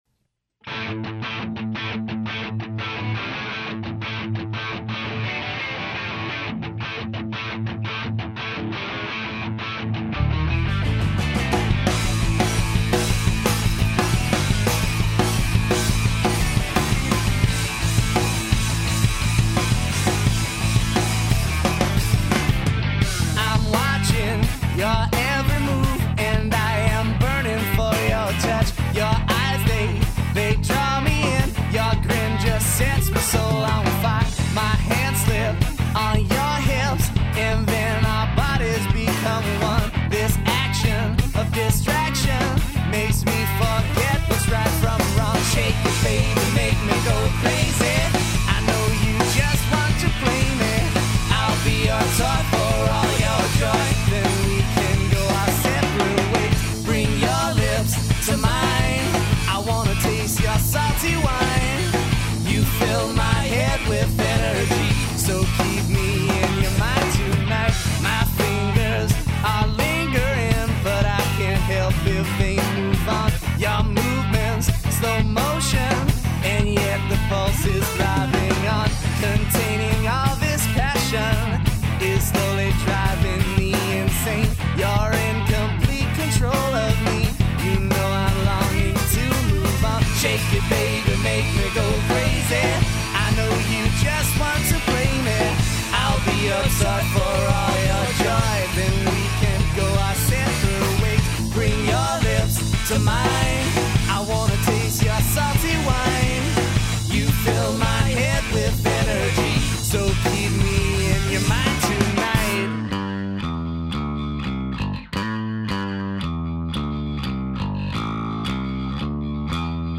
modern rock quartet